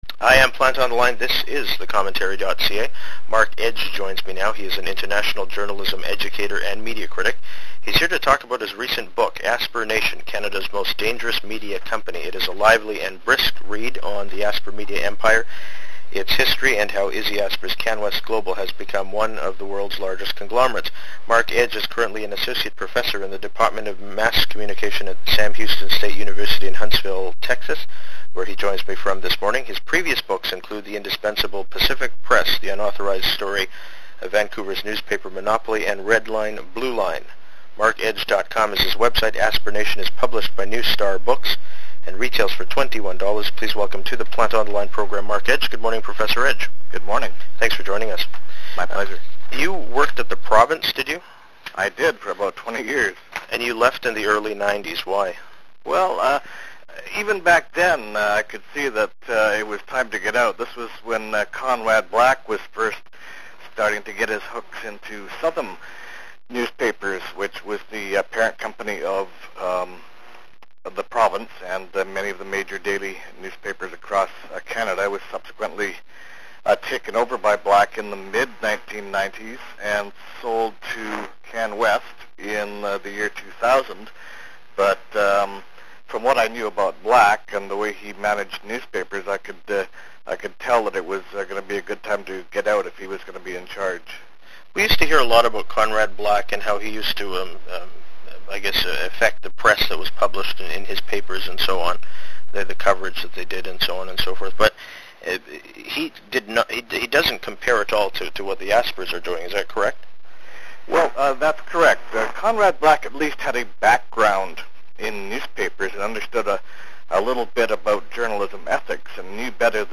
In an interview taped in January 2008